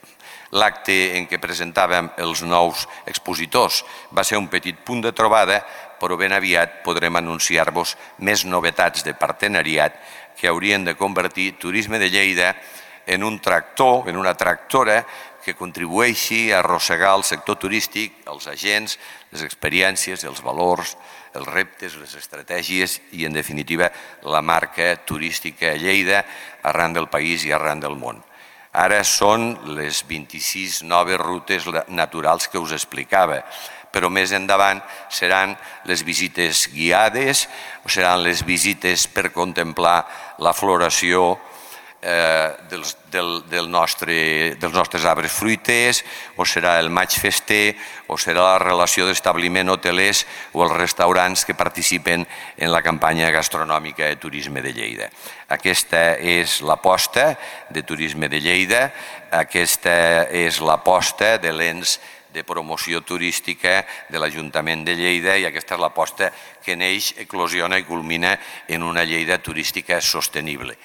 tall-de-veu-m-pueyo